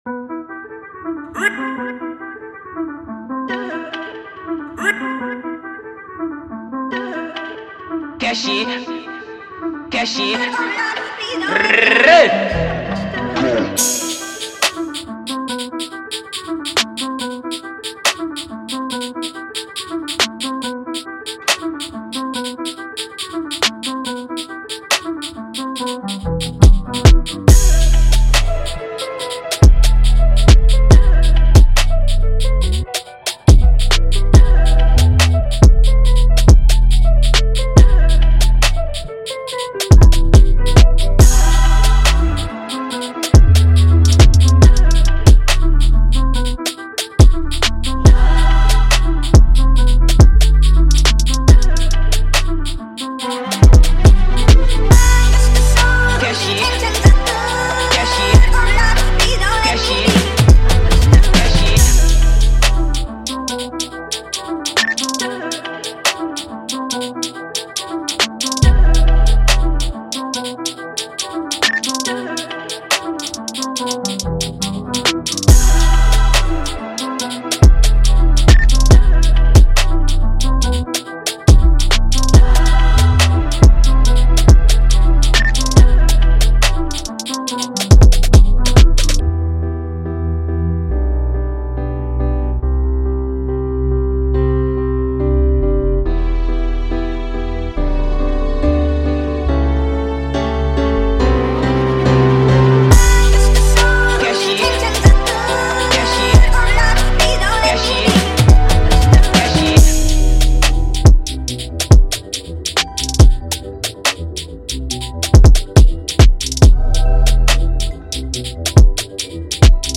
official instrumental
drill song